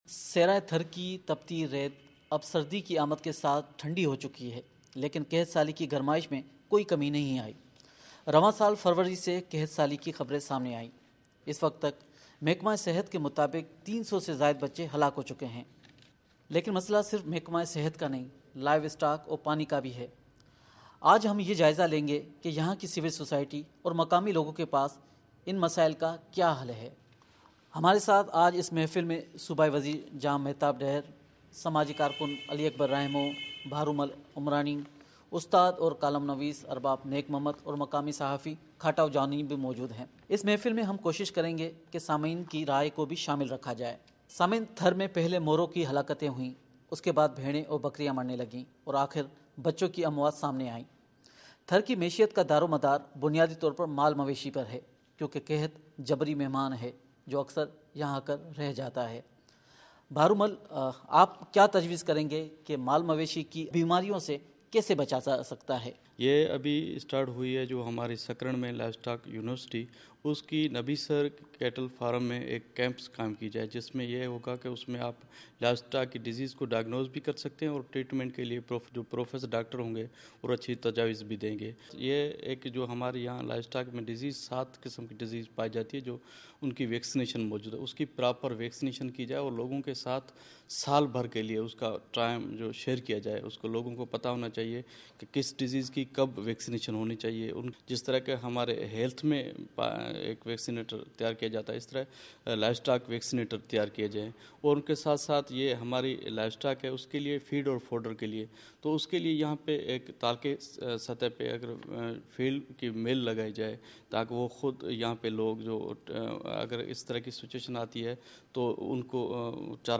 تھر میں قحط سالی پر مفصل گفتگو